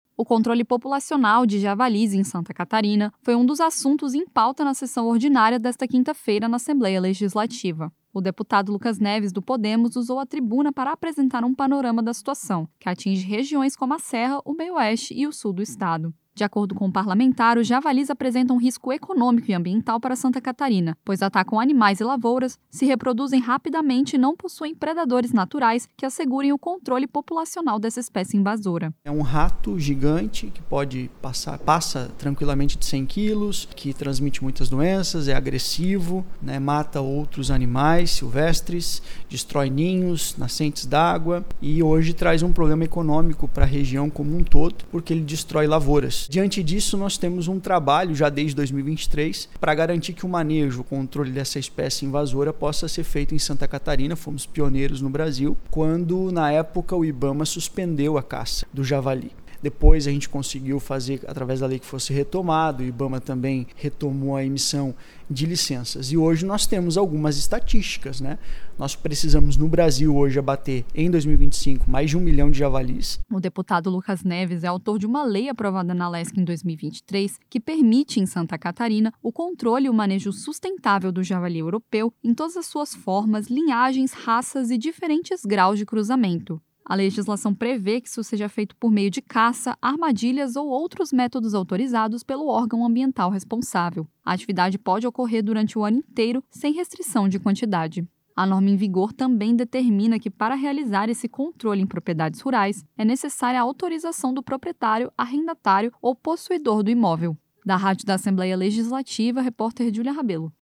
Entrevista com:
- deputado Lucas Neves (Podemos).